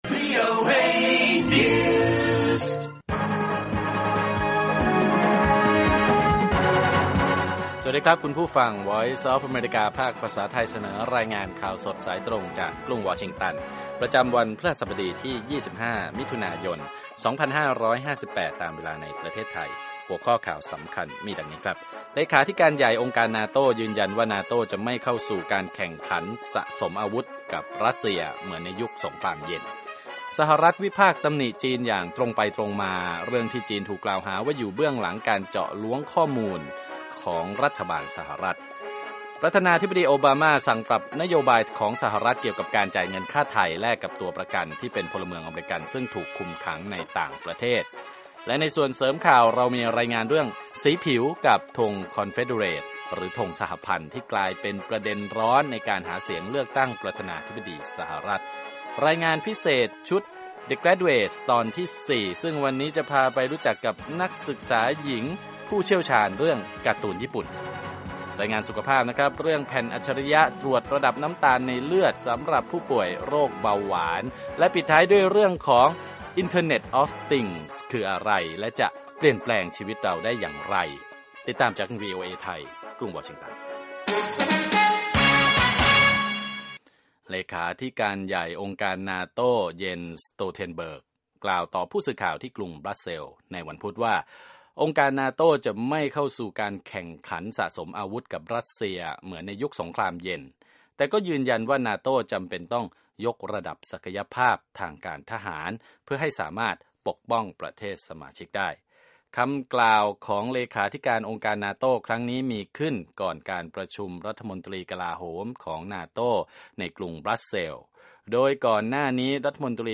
ข่าวสดสายตรงจากวีโอเอ ภาคภาษาไทย 8:30–9:00 น. พฤหัสบดีที่ 25 มิ.ย 2558